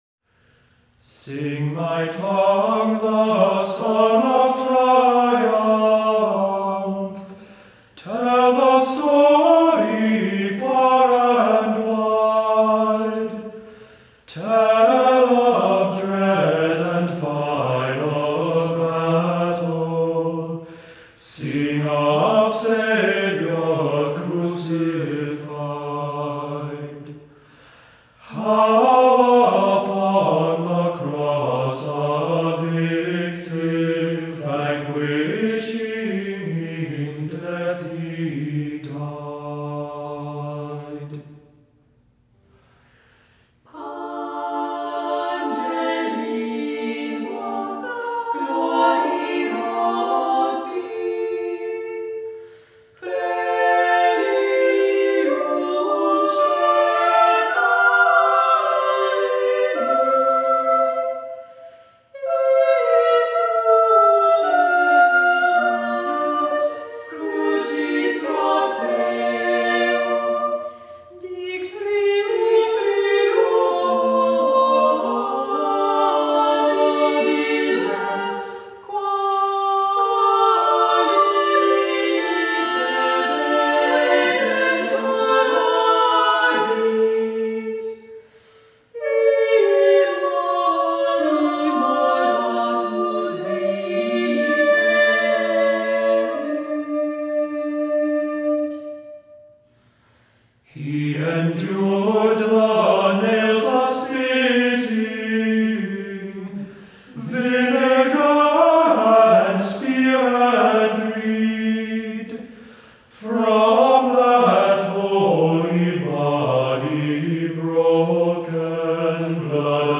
Voicing: a cappella,Assembly,3-part Choir